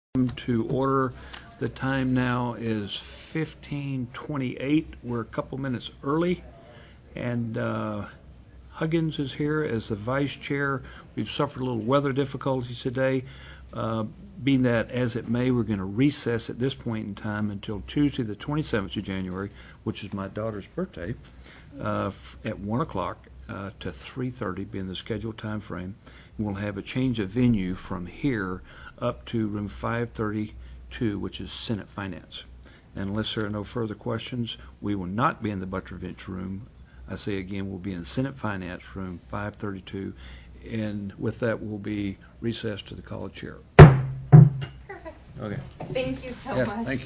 + teleconferenced
SENATE RESOURCES STANDING COMMITTEE